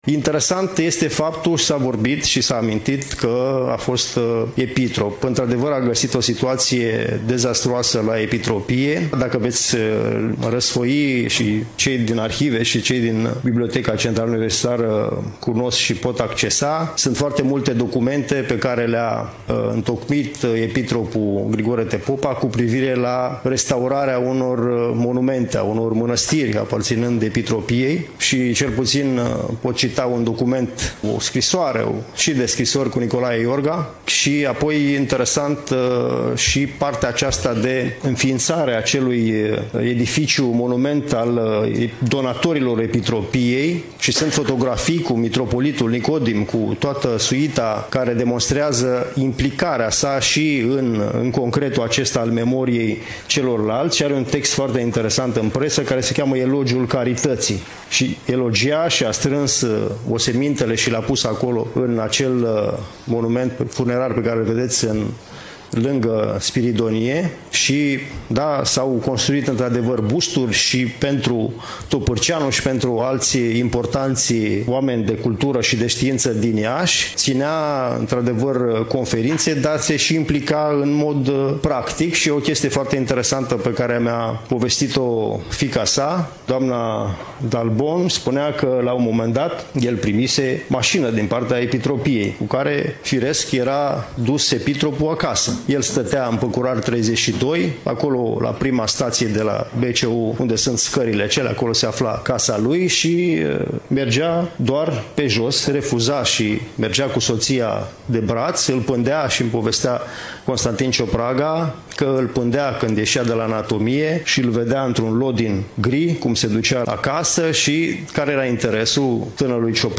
Prezentarea lucrării s-a desfășurat în incinta Muzeului Municipal „Regina Maria”, str. Zmeu, nr. 3.